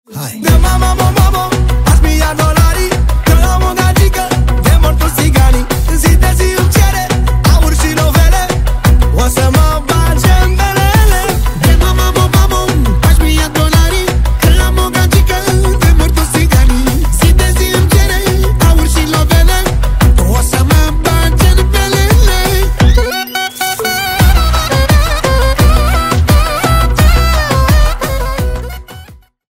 Manele